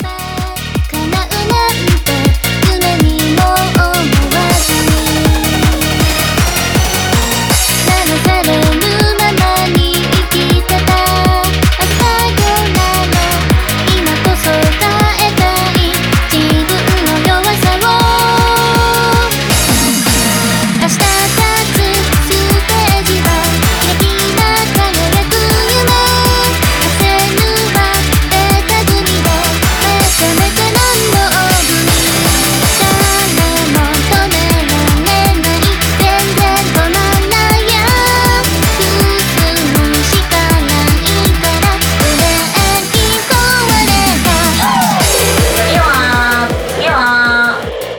EUROBEAT